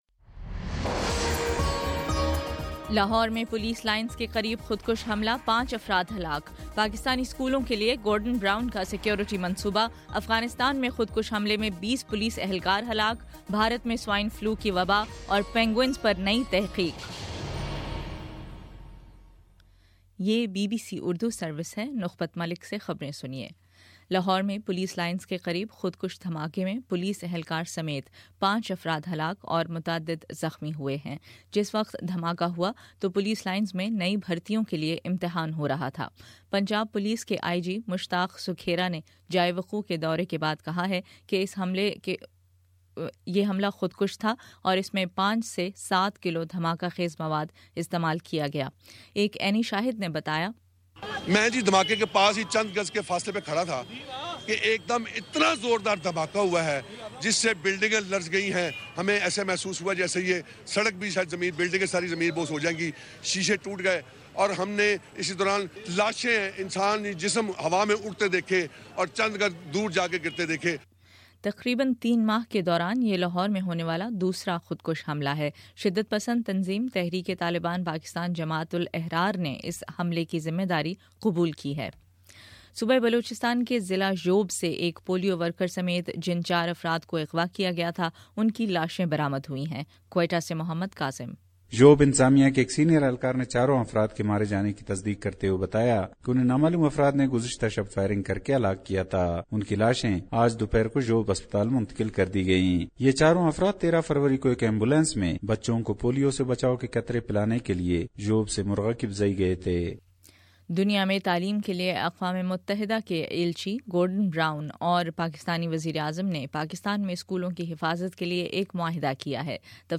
فروری17: شام سات بجے کا نیوز بُلیٹن